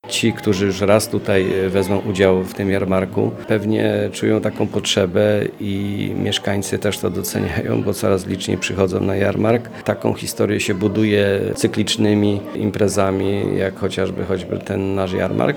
Mówi wójt Borowej Stanisław Mieszkowski.